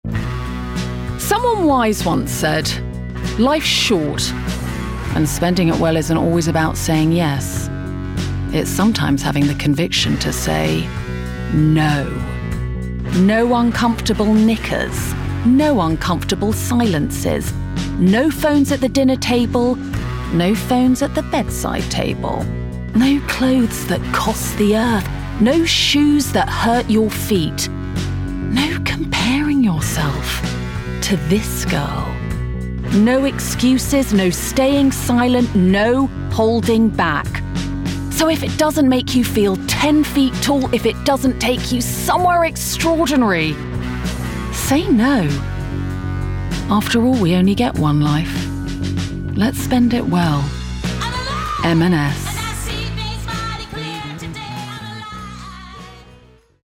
RP
Female
Assured
Bright
Dry
M&S COMMERCIAL